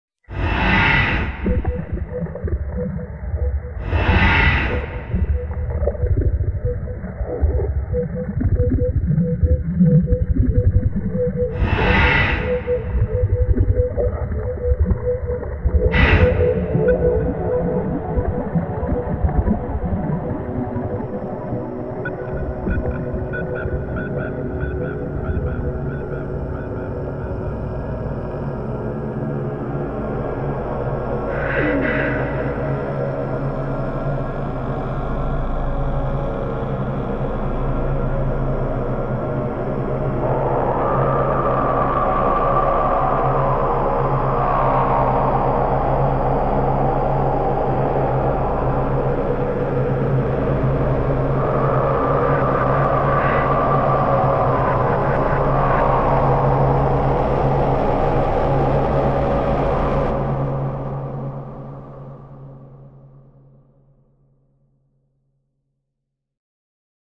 Film - Sound Design Excerpts
HR_Ambience.mp3